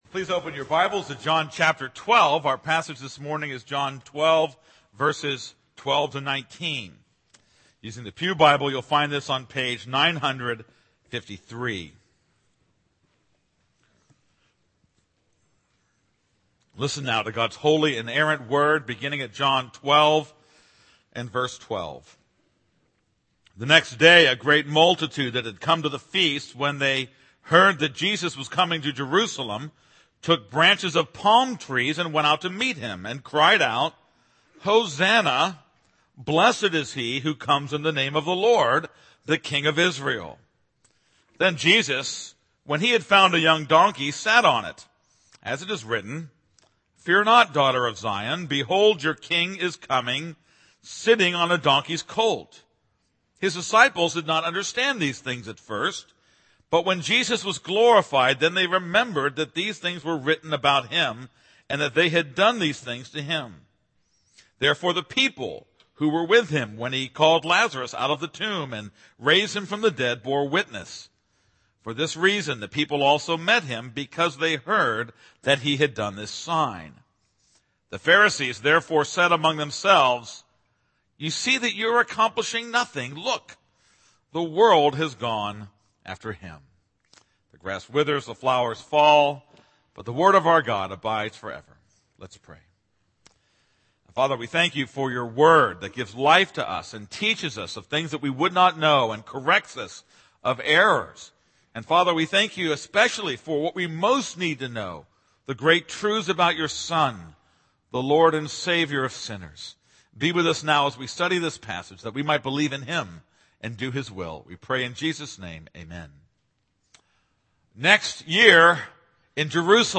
This is a sermon on John 12:12-19.